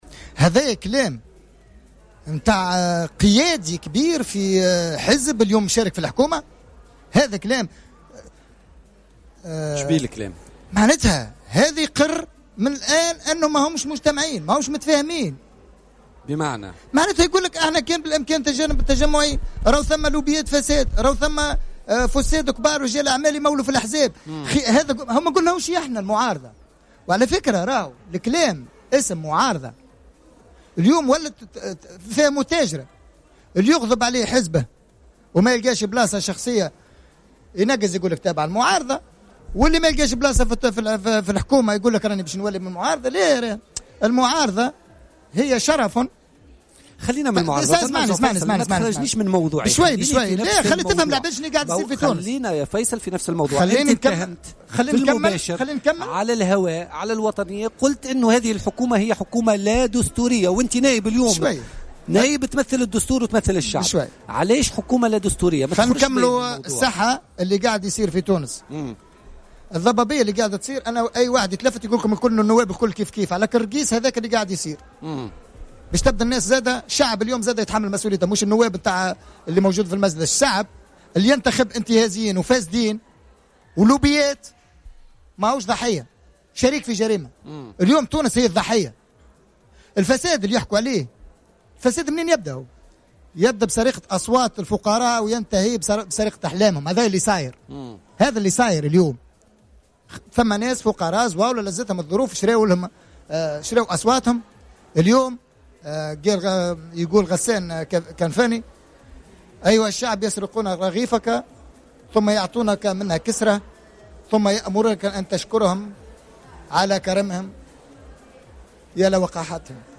وأضاف في مداخلة له اليوم في برنامج الحدث " تونس ضحية الفساد الذي بدأ بسرقة أصوات الفقراء وينتهي بسرقة أحلامهم".